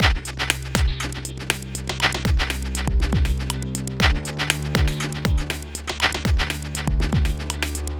Doing Stuff (Full) 120 BPM.wav